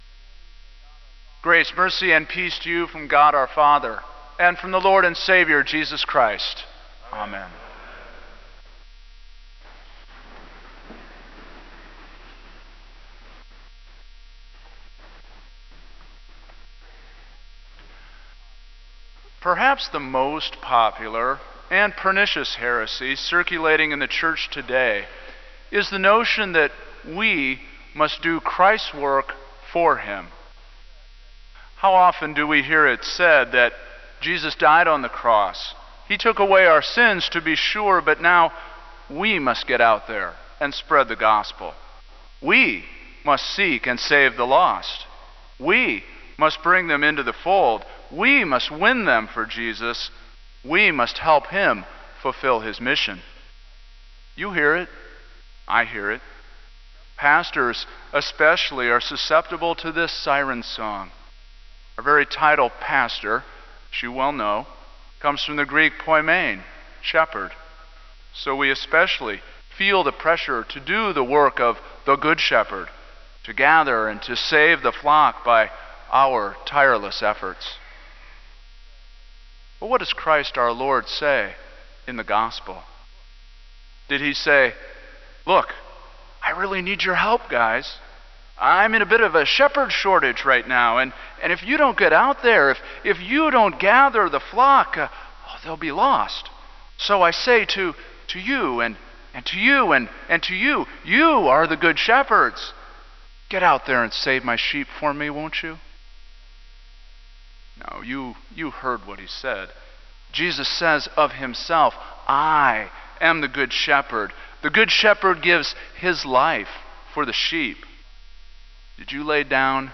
Kramer Chapel Sermon - May 10, 2000